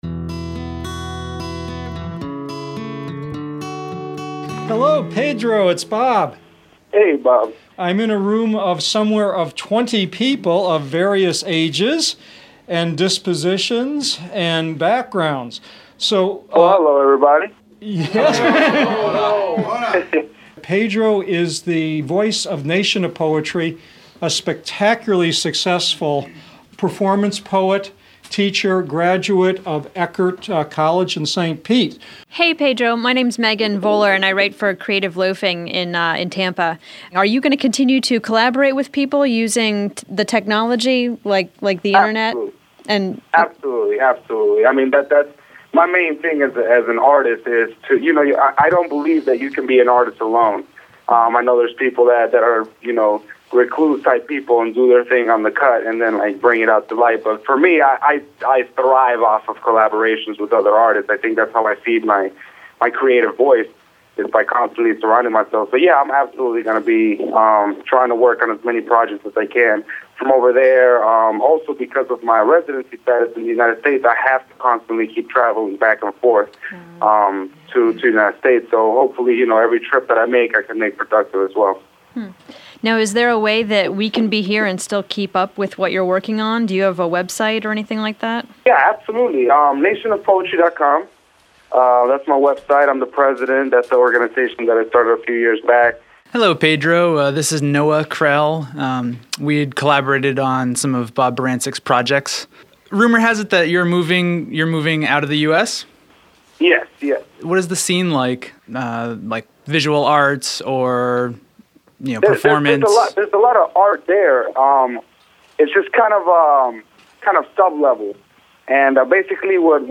These specific CreativeShare Digital Dialogues were recorded on Peaks Island, Maine on August 11th and 12th of 2008.